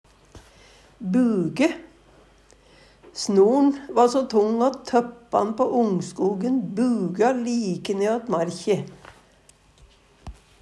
buge - Numedalsmål (en-US)